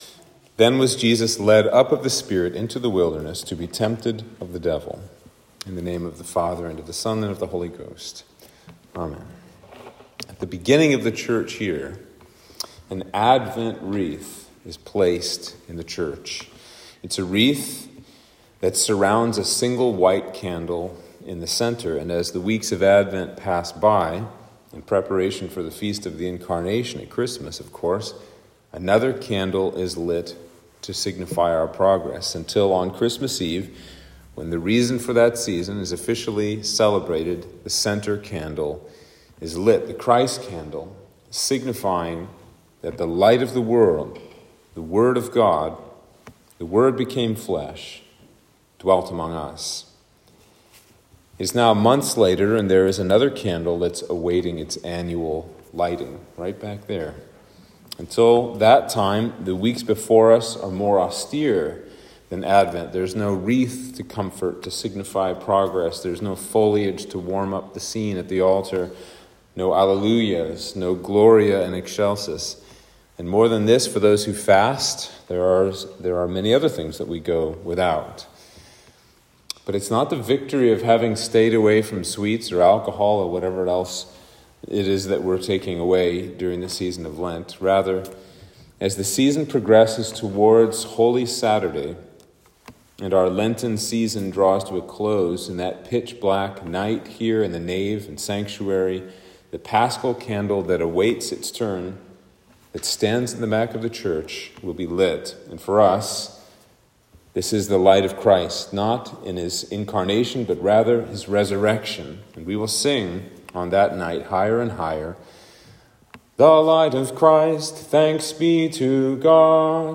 Sermon for Lent 1 - 2023